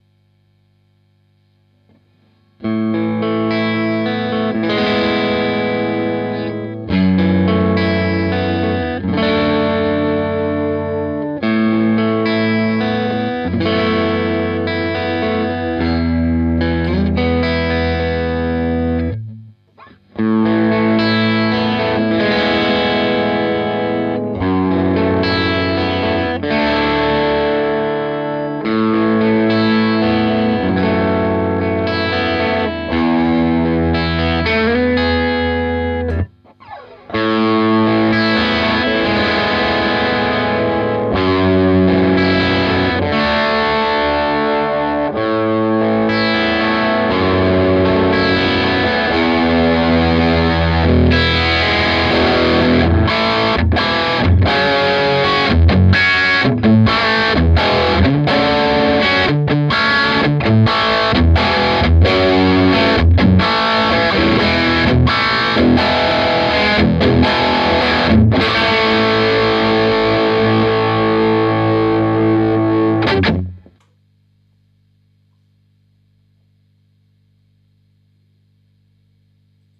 In meinen Ohren geht der Kleine ziemlich britisch zu Werke, was durch den Greenback in meinem Fall wahrscheinlich auch noch mehr betont wird.
Ich schalte durch alle Pickup-Positionen, jeweils mit  runter geregeltem Volume und danach voll aufgedreht. Die Einstellungen beim Amp waren bis auf den Gain-Regler (im Scheithauer-Clip voll auf, im Strat-Clip auf 12 Uhr) identisch.
Career/Felleretta 5 Watt - Scheithauer AS Custom